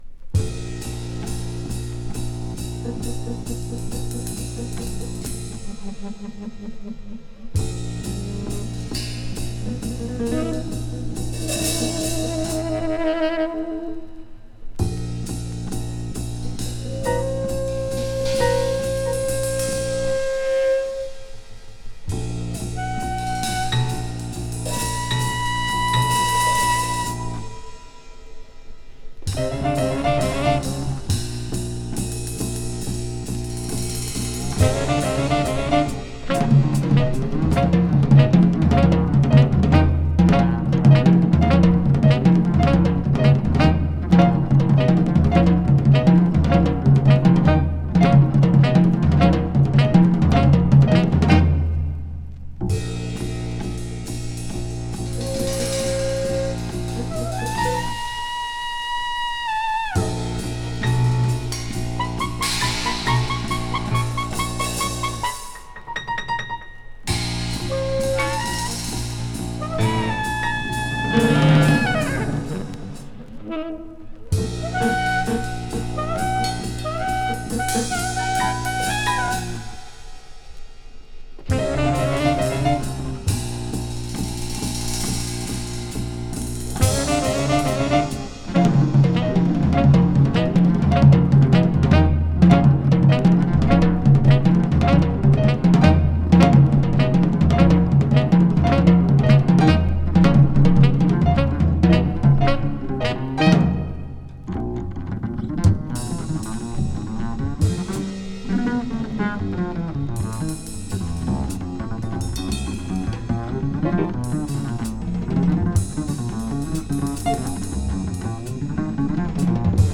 Multicultural Contemporary Jazz!
フリーキーな
【CONTEMPORARY】【FUSION】